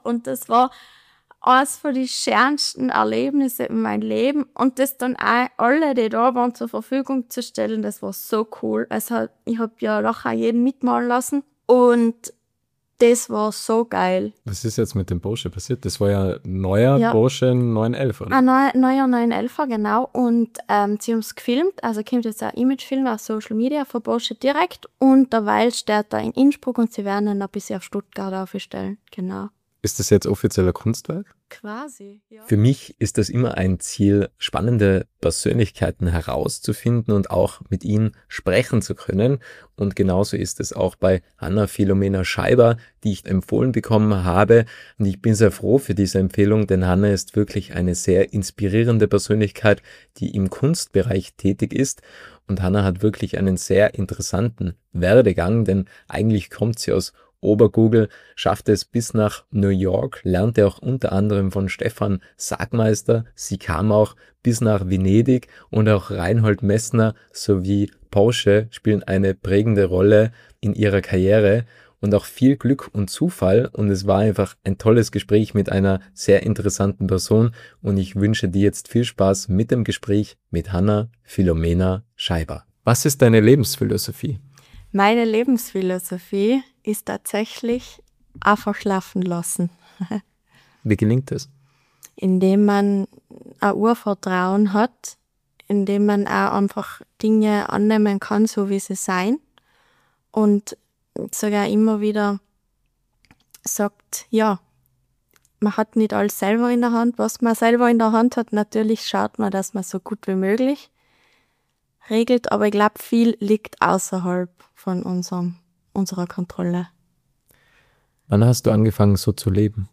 Das Podcast- Format little talks vermittelt in 30-minütigen Interviews spannende Impulse, welche bewegen, zum Nachdenken anregen und Identifikationspotenziale schaffen.